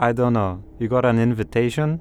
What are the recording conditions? Update Voice Overs for Amplification & Normalisation